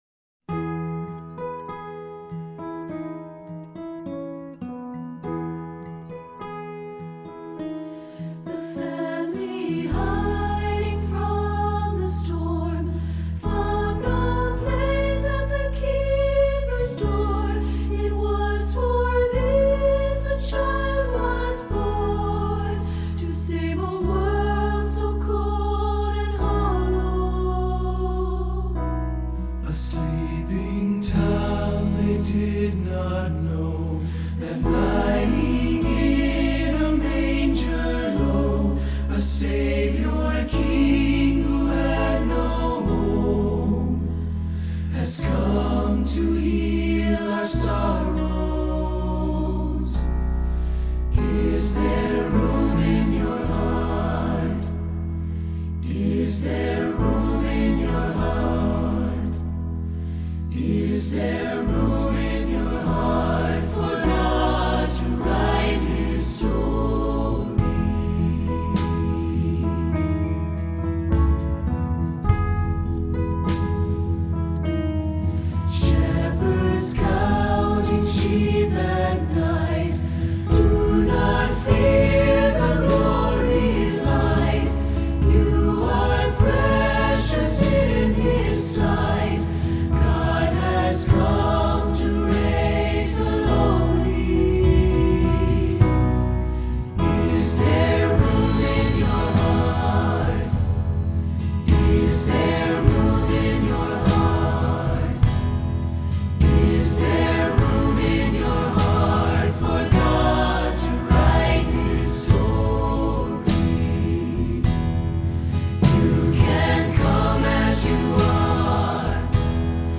SATB
Choral Christmas/Hanukkah